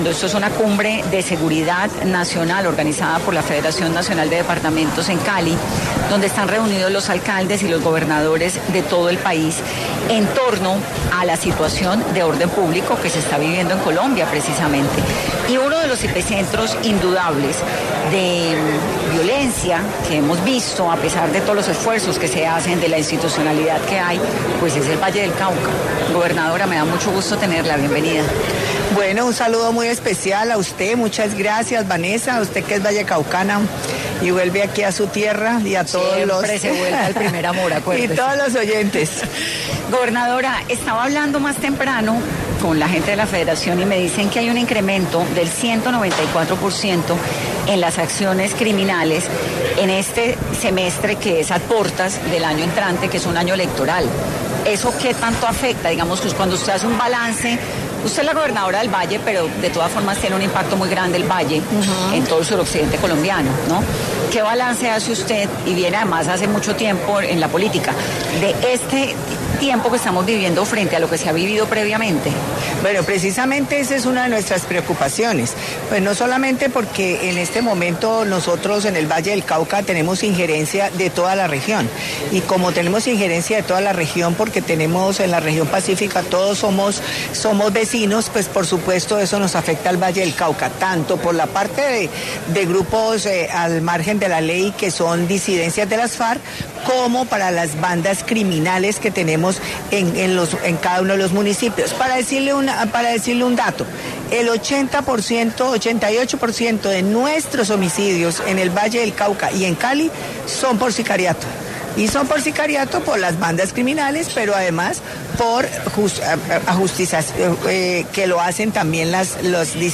Dilian Francisca Toro, gobernadora del Valle del Cauca, habla de la seguridad en el departamento en 10AM de Caracol Radio